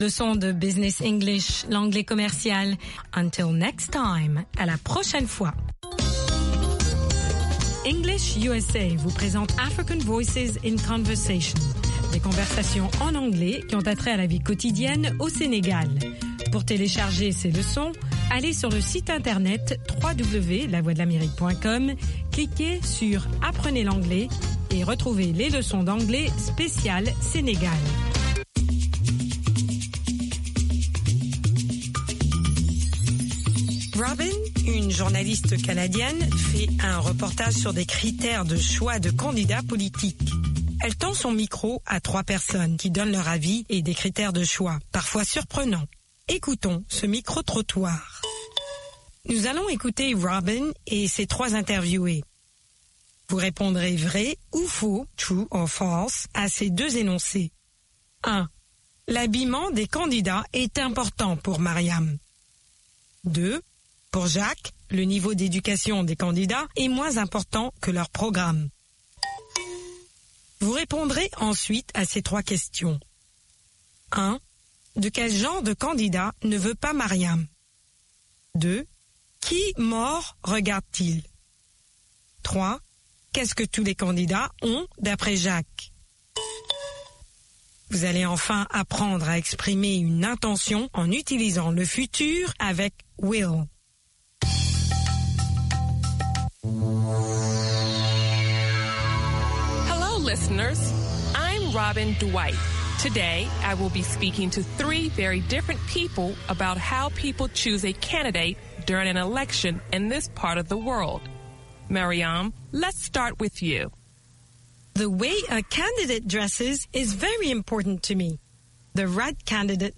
AFRICAN VOICES IN CONVERSATION
Des conversations en anglais américain par des Africains, qui ont trait à la vie quotidienne au Sénégal.